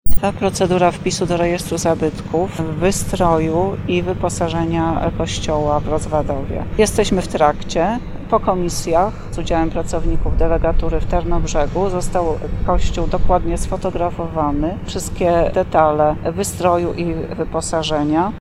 O podjętych działaniach mówiła wojewódzka konserwator zabytków Beata Kot.